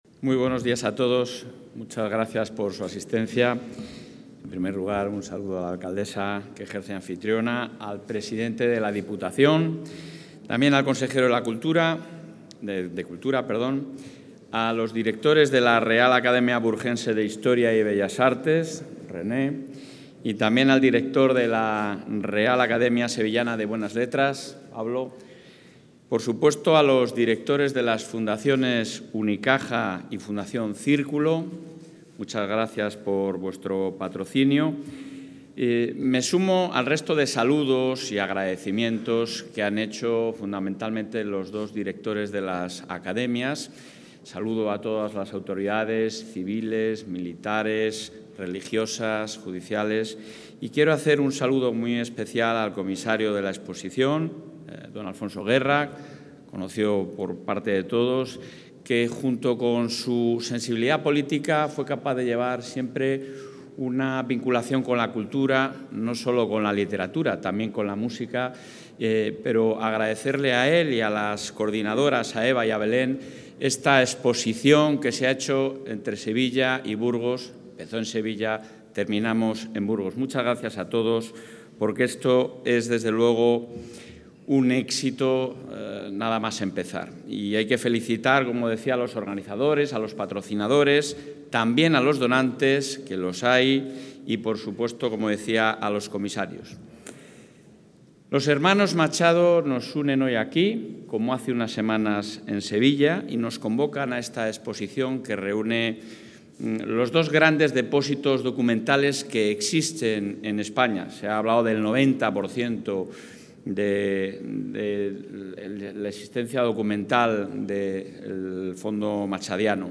Intervención del presidente de la Junta.
El presidente de la Junta de Castilla y León, Alfonso Fernández Mañueco, ha participado hoy en la Fundación Círculo, en Burgos, en la inauguración de la exposición 'Los Machado. Retrato de Familia', organizada por la Real Academia Burgense de Historia y Bellas Artes, la Real Academia Sevillana de Buenas Letras, y la Fundación Unicaja.